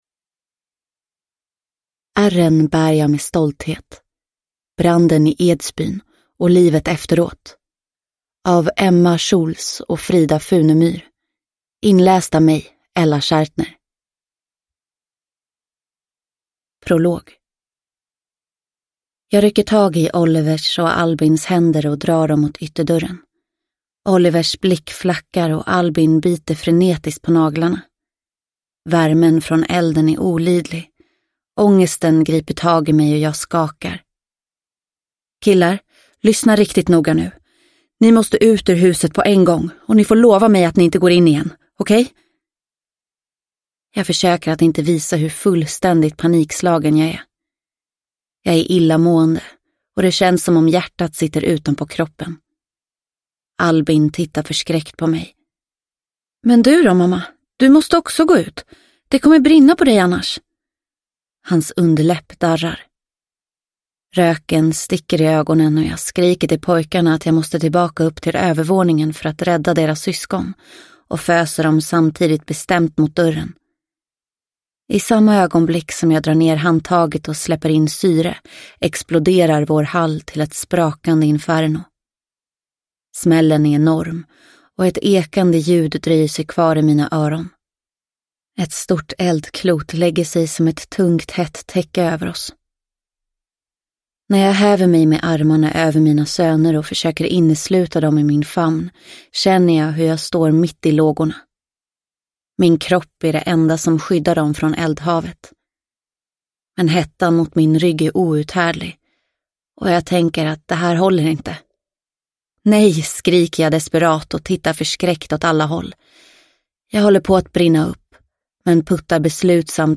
Ärren bär jag med stolthet : branden i Edsbyn och livet efteråt – Ljudbok – Laddas ner